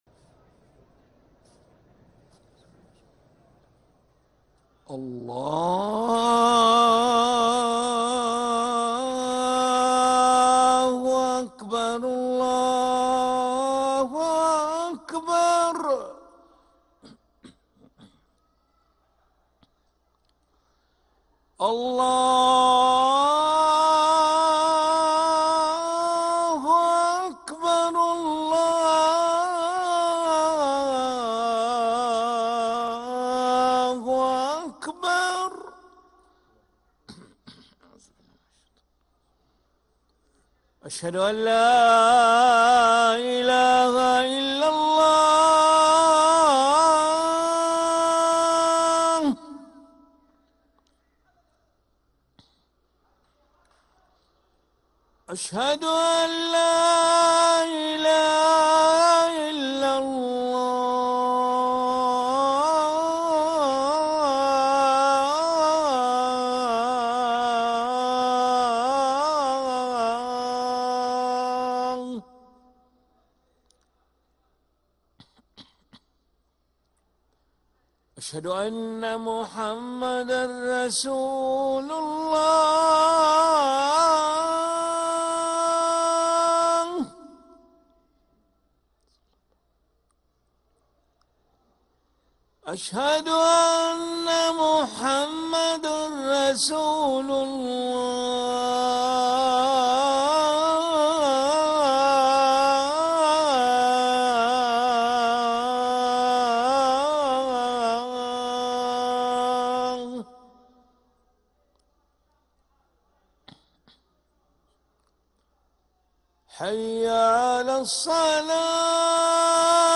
أذان العشاء للمؤذن علي ملا الأحد 12 ربيع الأول 1446هـ > ١٤٤٦ 🕋 > ركن الأذان 🕋 > المزيد - تلاوات الحرمين